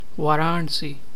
Hi-Varanasi.ogg.mp3